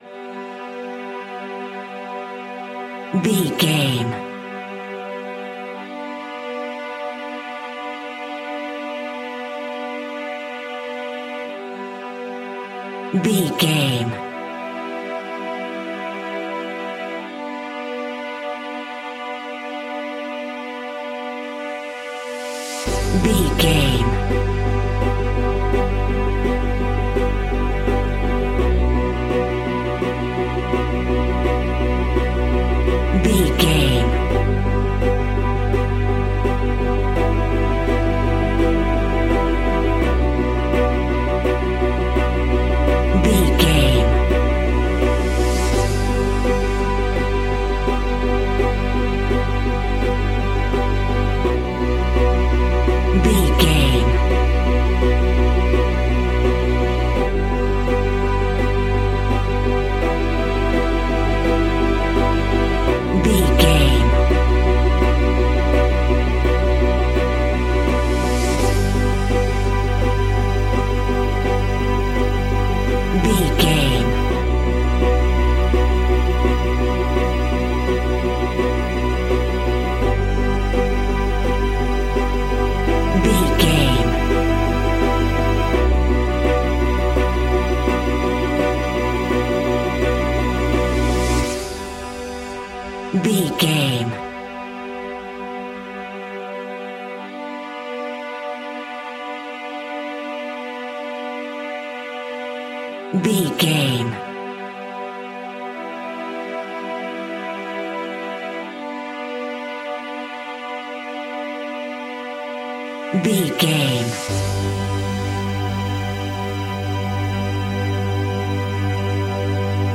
Epic / Action
Fast paced
In-crescendo
Uplifting
Aeolian/Minor
dramatic
powerful
brass
percussion
synthesiser